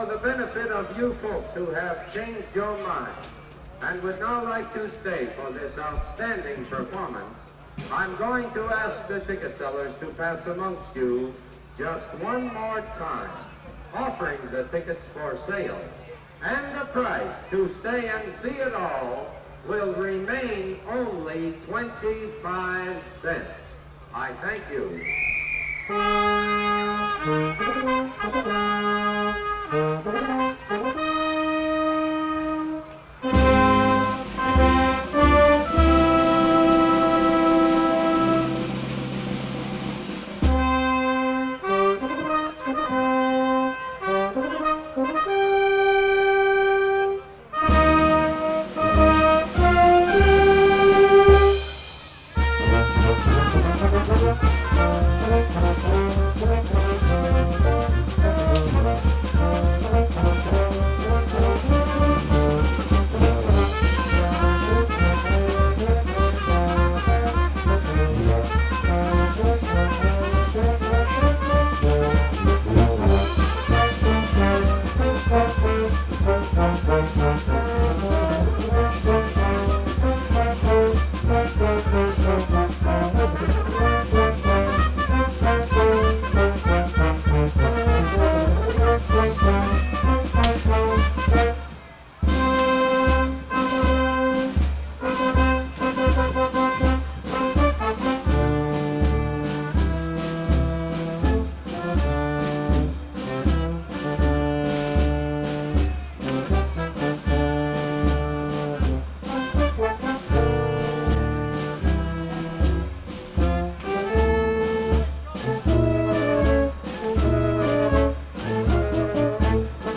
Recorded on September 1, 1961, in Shillington, PA.
unidentified waltz                        (2:01 – 2:39)
unidentified galop                        (2:40 –  3:53)